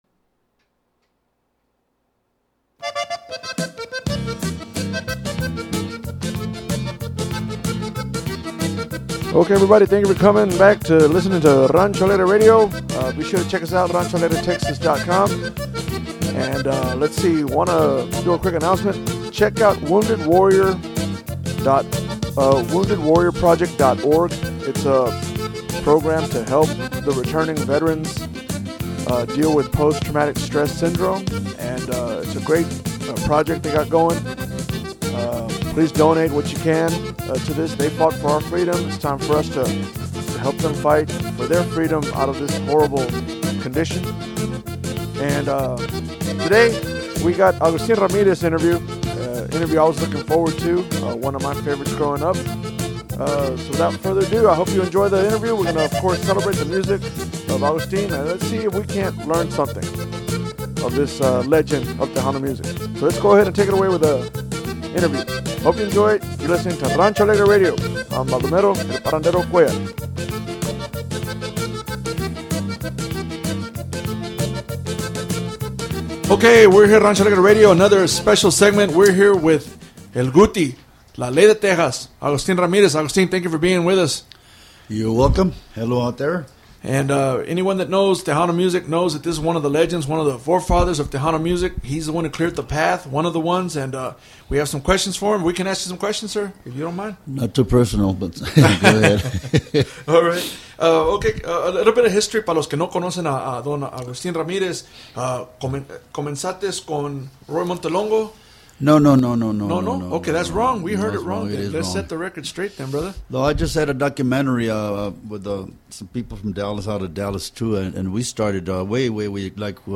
Rancho Alegre Interview